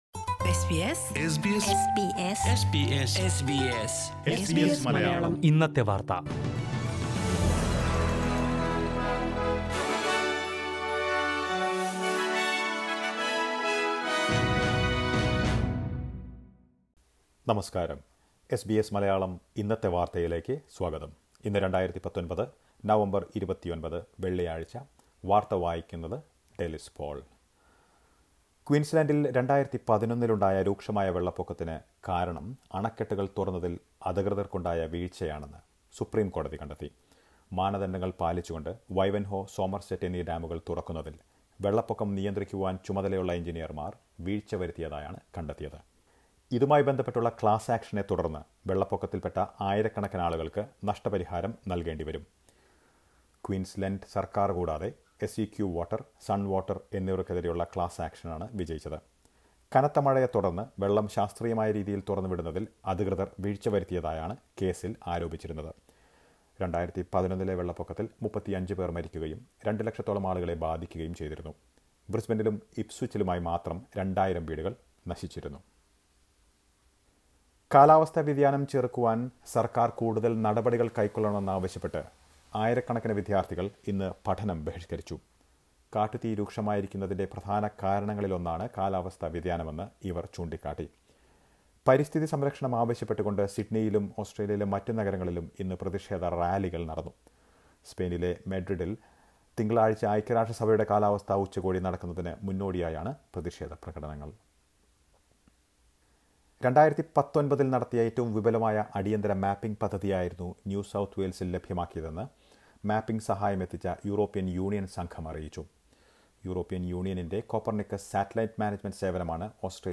2019 നവംബർ 29ലെ ഓസ്ട്രേലിയയിലെ ഏറ്റവും പ്രധാന വാർത്തകൾ കേൾക്കാം...